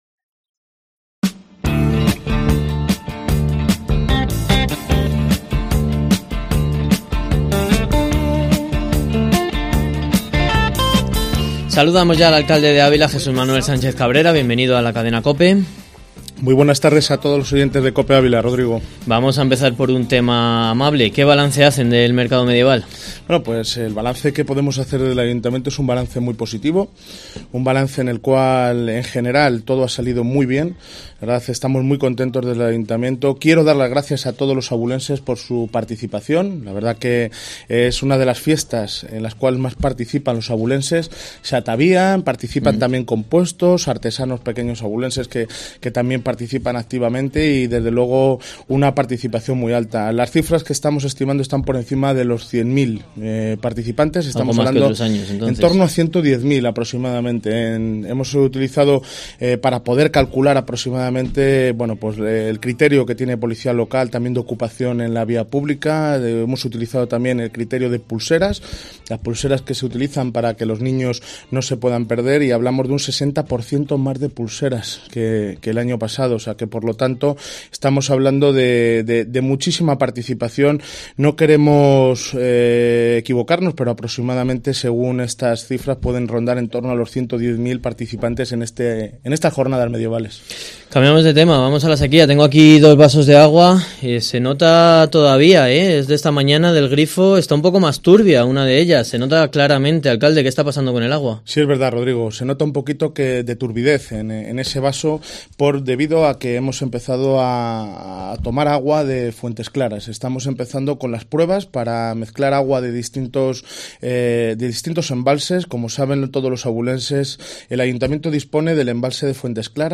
AUDIO: Entrevista al alcalde de Ávila Jesús Manuel Sánchez Cabrera en Mediodía COPE Ávila 09/09/2019